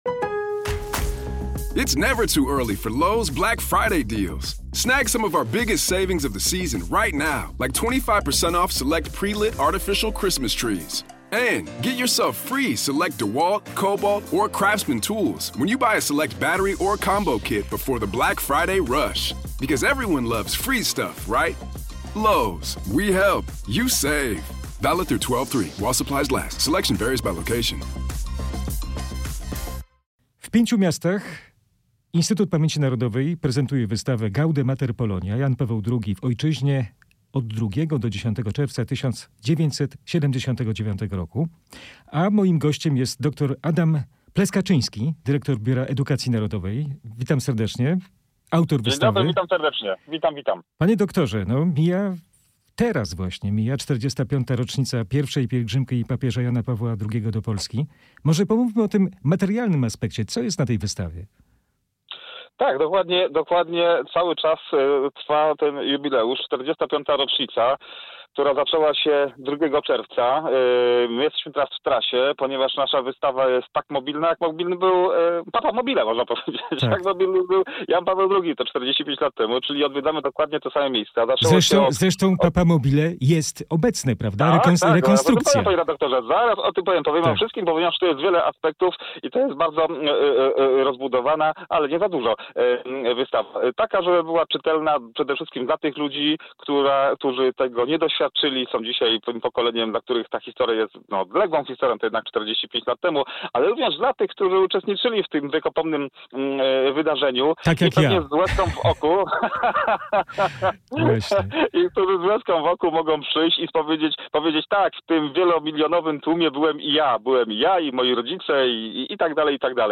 Papamobile w pielgrzymce po Polsce - rozmowa